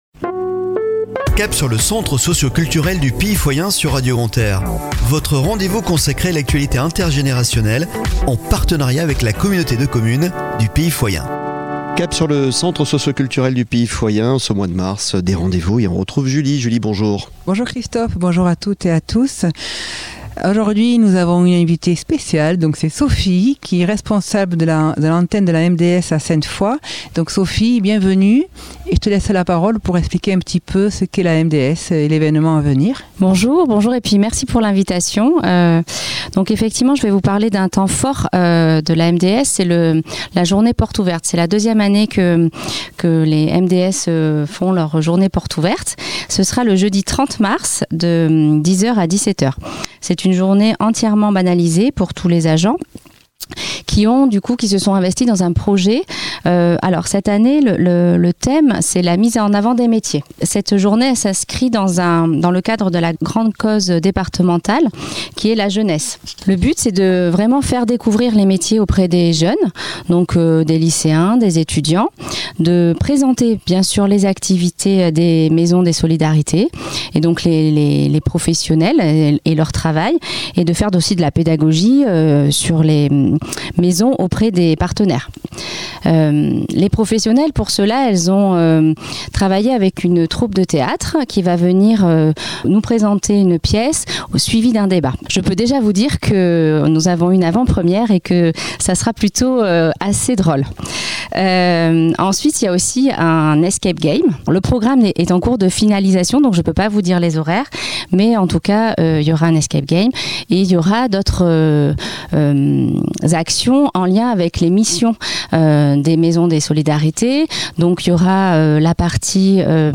Chronique de la semaine du 13 au 19 Mars 2023 !